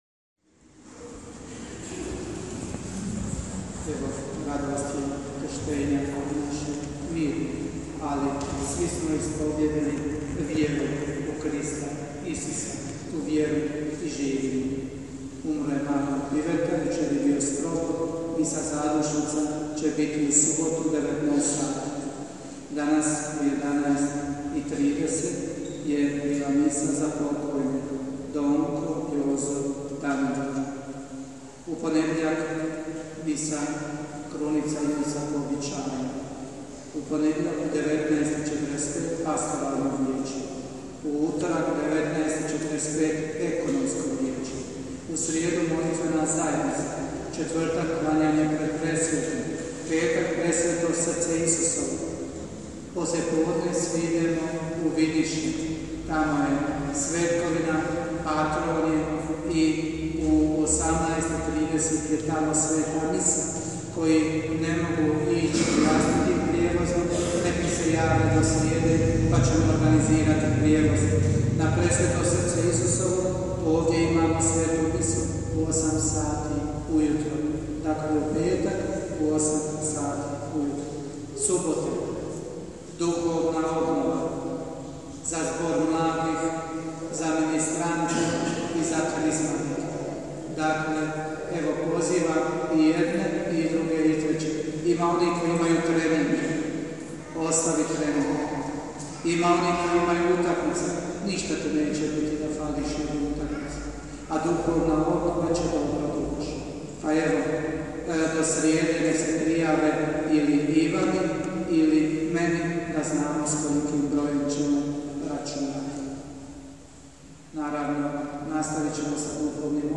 OBAVIJESTI:
OBAVIJESTI I BLAGOSLOV – 9. NEDJELJA KROZ GODINU C